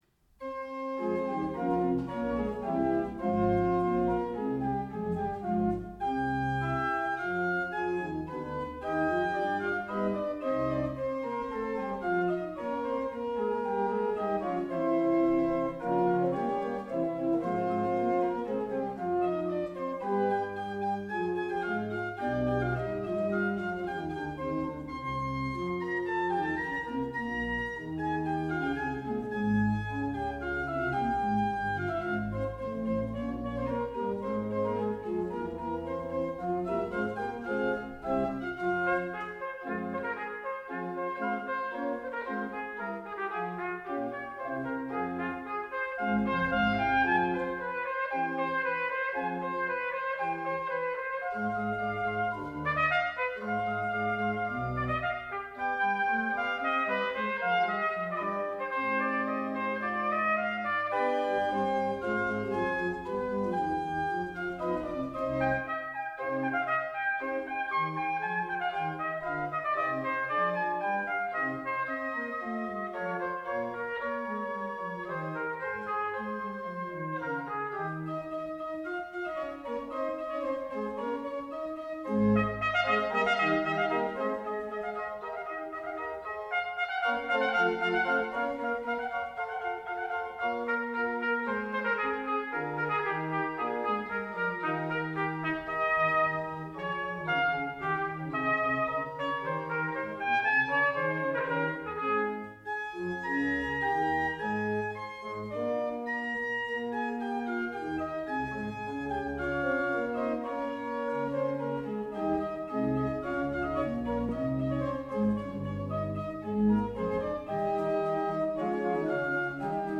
bearbeitet für Orgel und Trompete.